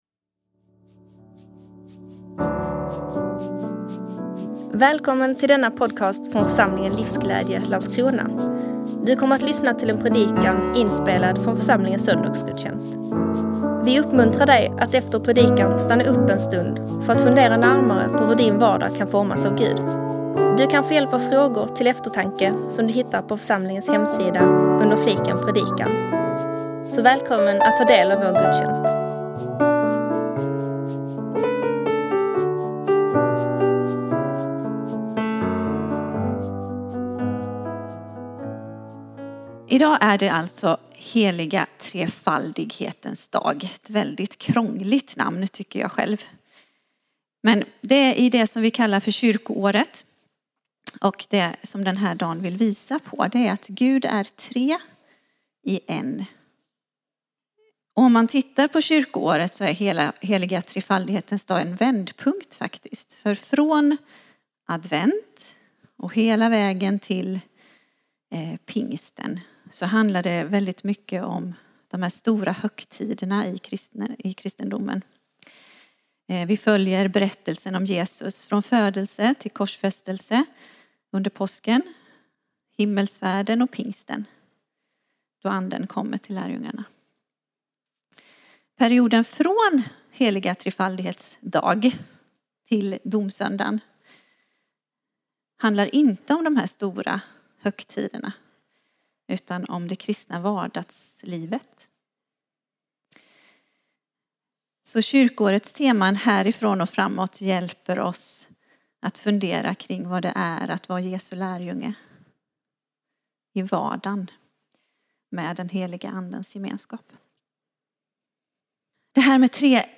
Predikan – Livsglädje